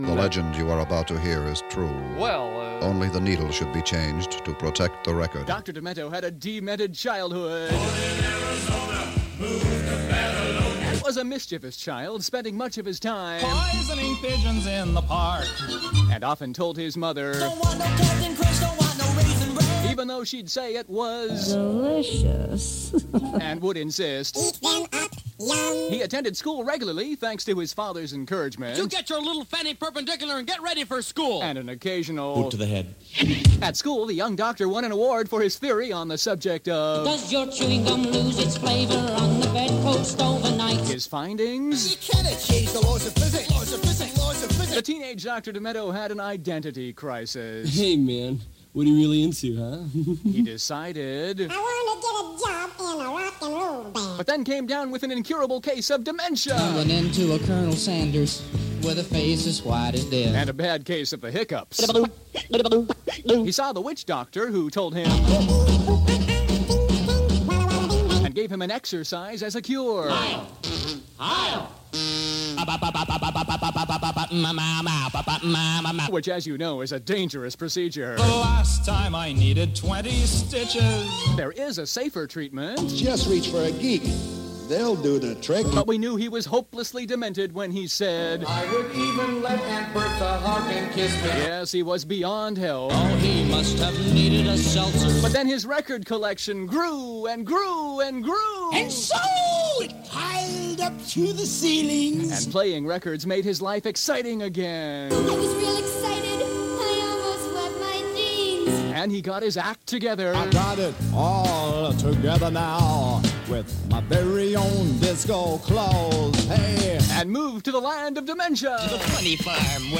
6/1/25: On the occasion of Dr. Demento's retirement announcement after 55 years, let's revist "The Dr. Demento Story" from 1991, which features clips from many of the greatest hits in the Land of Dementia!